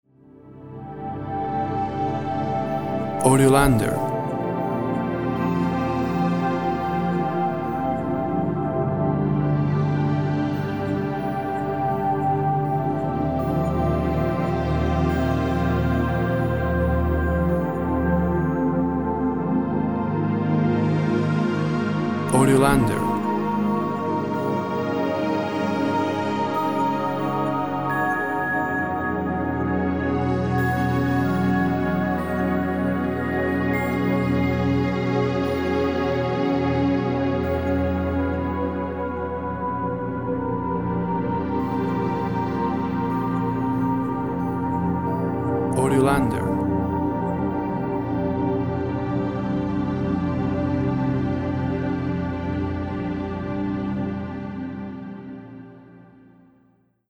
Thick synth sounds with solo pad and bells.
WAV Sample Rate 24-Bit Stereo, 44.1 kHz
Tempo (BPM) 56